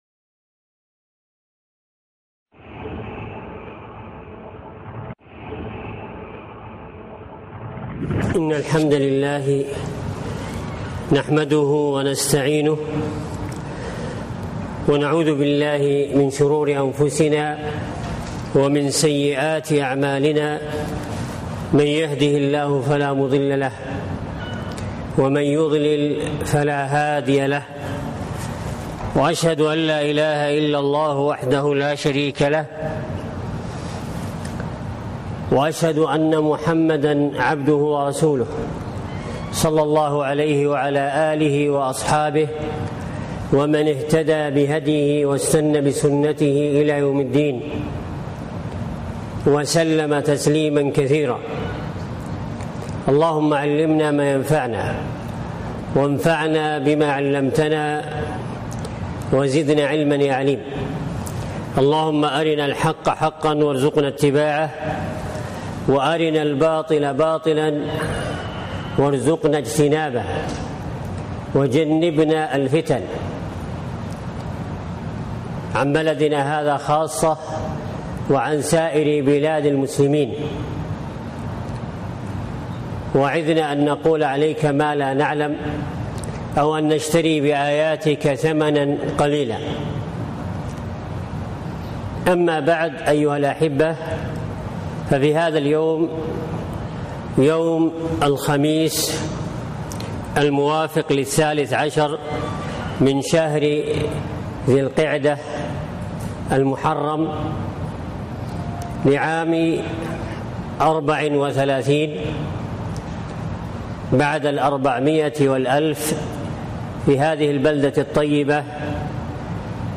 التمسك بالسنه - محاضرة - دروس الكويت